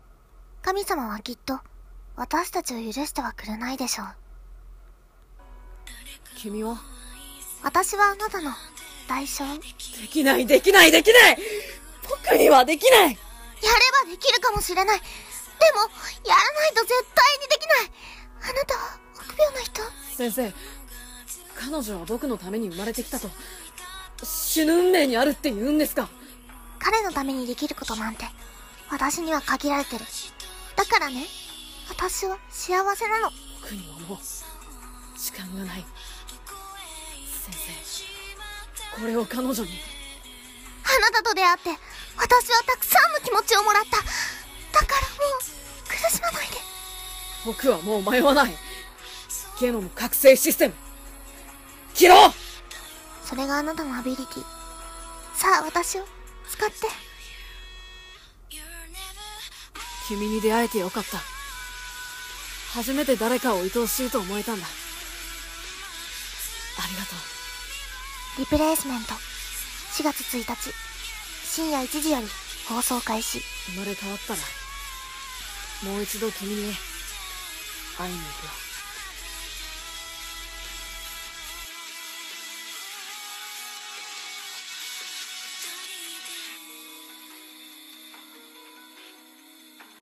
【声劇】アニメ告知風CM『リプレイスメント』 少年
少女